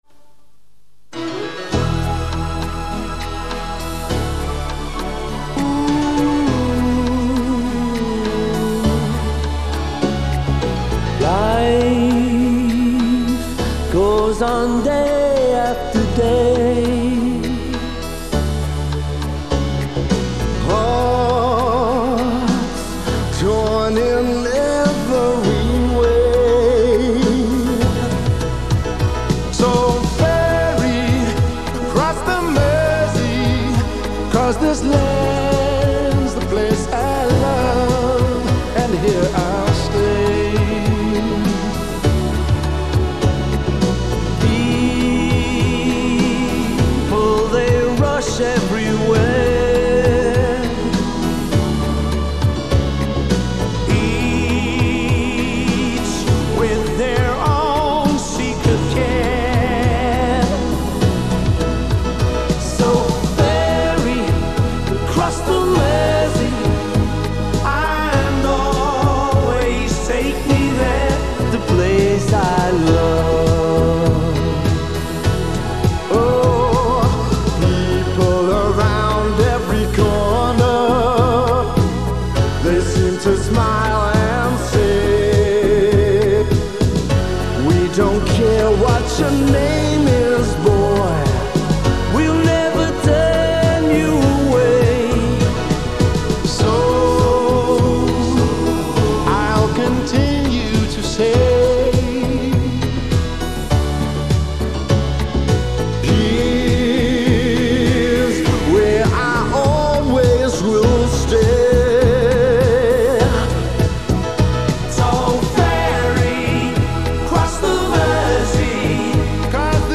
запись с кассеты